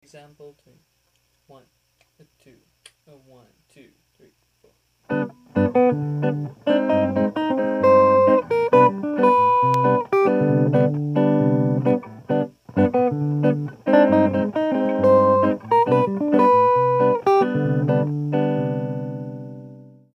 For ease of understanding, I've written all the examples as Major II-V-I progressions in the key of C Major.
(Listen) - Rhythm is probably the most important element here accentuating the large interval skips by the open voiced triads.